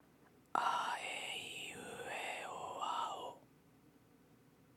ささやき声・ひそひそ声を出す
音量注意！
単純に『めちゃくちゃ小さい声で喋ってみましょう』という練習です。
falsetto-first-step-01.mp3